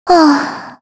Xerah-Vox_Sigh_kr.wav